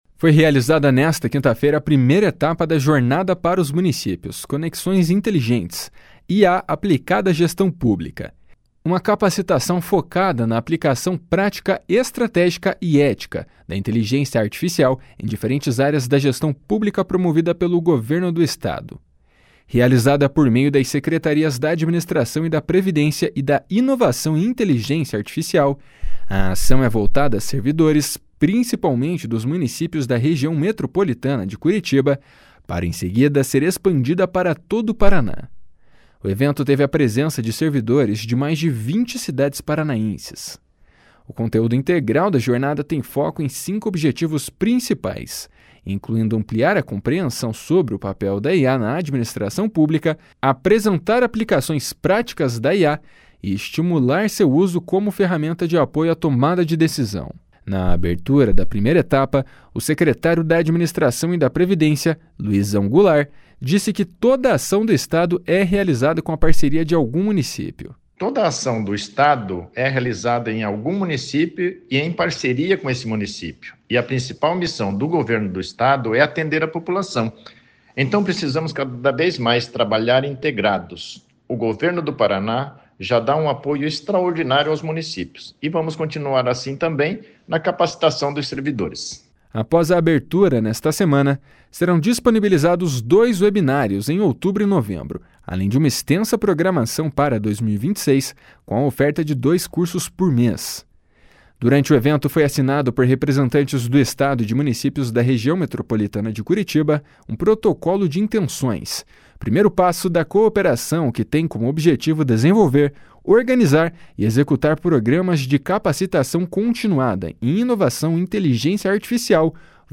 // SONORA LUIZÃO GOULART //